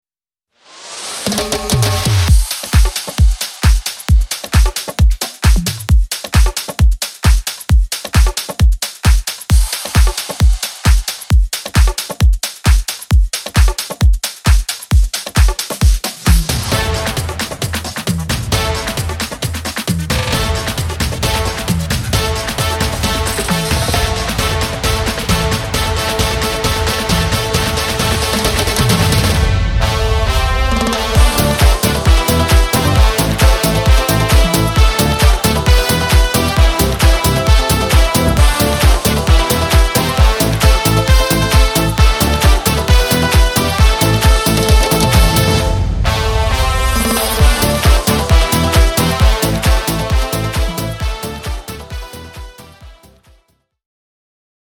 gorący kawałek w nowej odsłonie aranżacyjnej
Instrumental
Dance